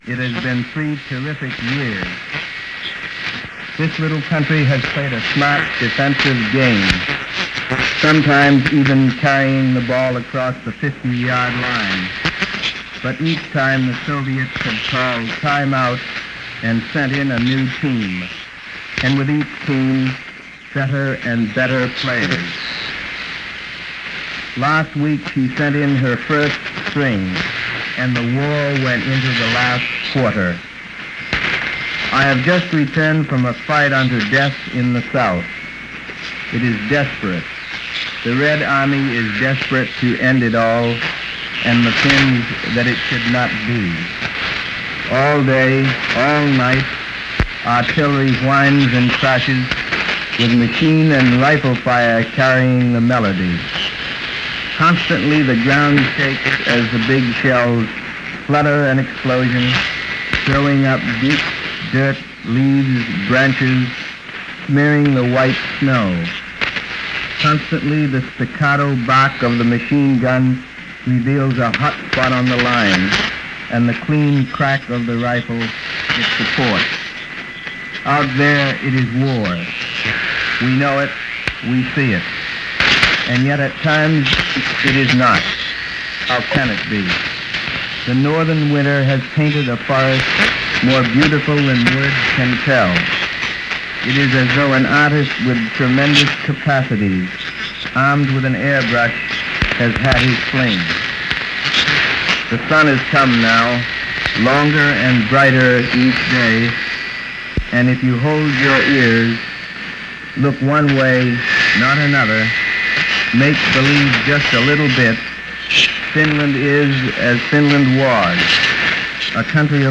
March 2, 1940 – News from Helsinki Finland, as reported by Life Magazine photographer Carl Mydans and relayed to America via Finnish Radio.
Because this broadcast was made via shortwave, the sound fades in and out and is unintelligible in places – this was the highest technology of the time, and even though it was far from ideal, it gave the news an immediacy that wasn’t available during the previous war.
March-2-1940-Finnish-Radio-Carl-Mydans-Report-from-Helsinki.mp3